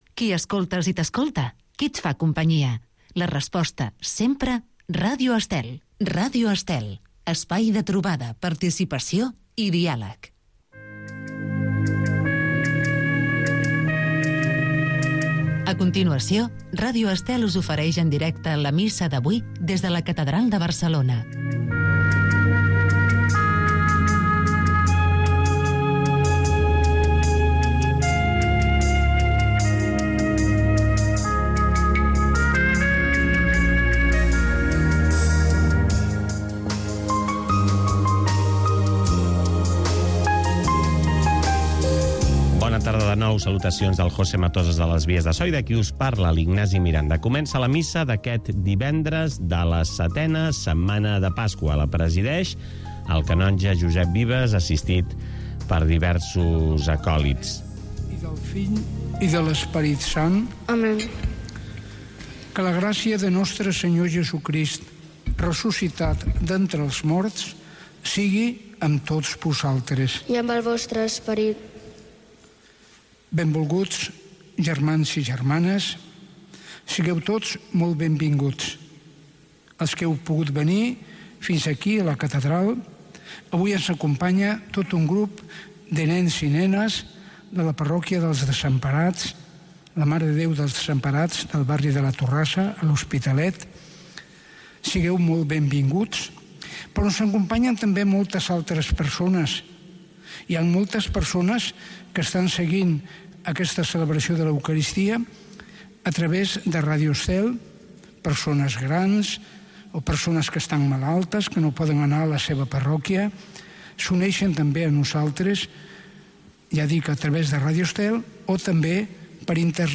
Cada dia pots seguir la Missa en directe amb Ràdio Estel.